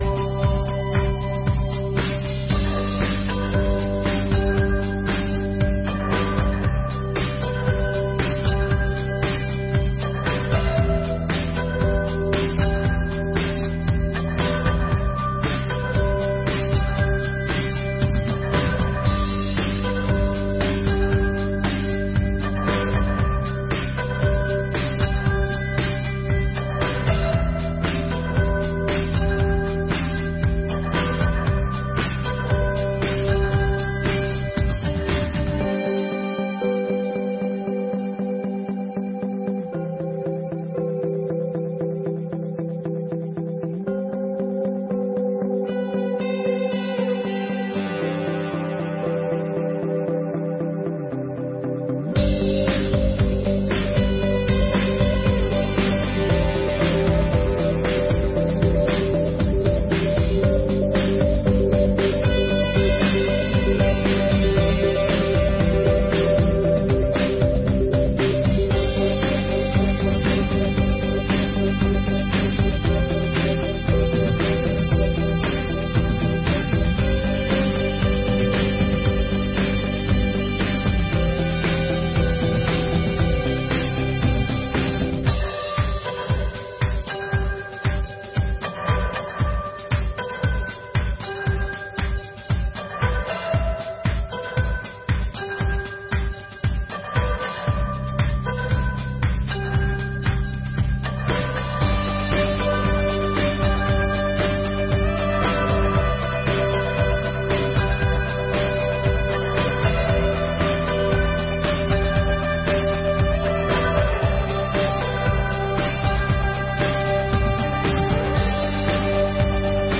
25ª Sessão Ordinária de 2024